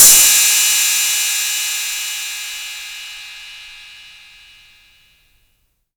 Tuned cymbal samples Free sound effects and audio clips
Original creative-commons licensed sounds for DJ's and music producers, recorded with high quality studio microphones.
crash-single-hit-d-sharp-key-09-V6w.wav